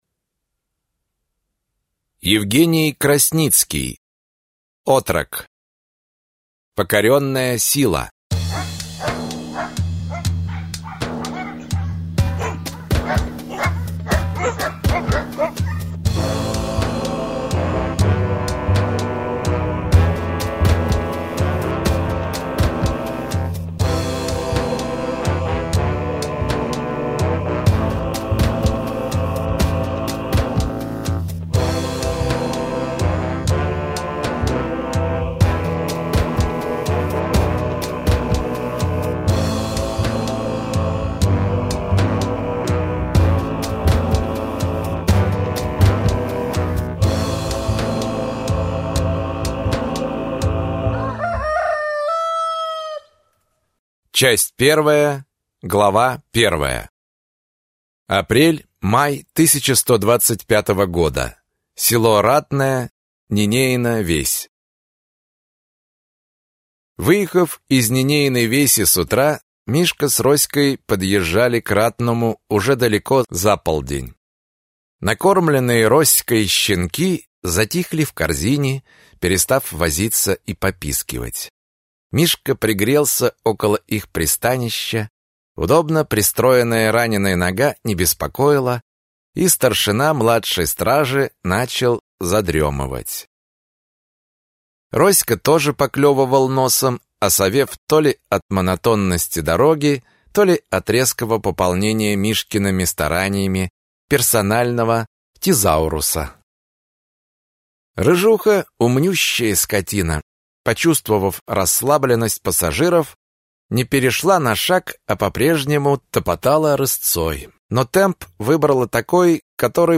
Аудиокнига Отрок. Покоренная сила | Библиотека аудиокниг